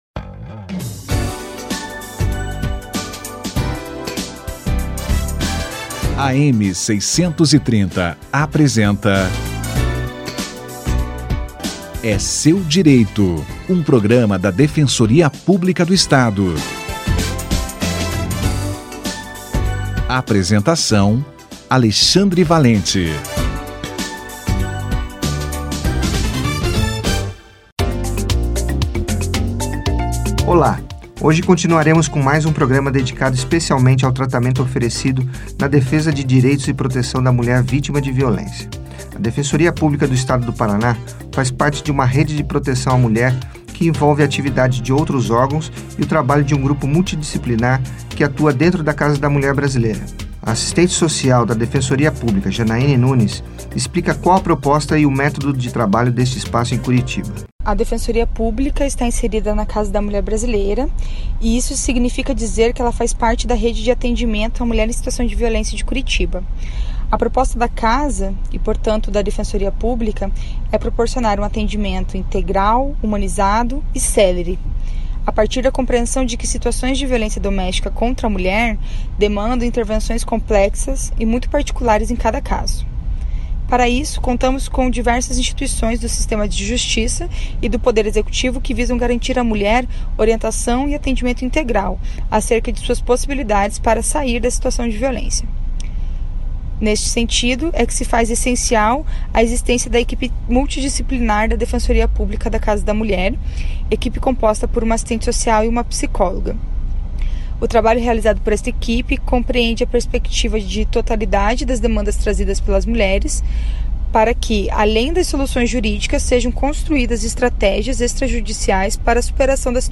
Atendimento assistente social na Casa da Mulher - Entrevista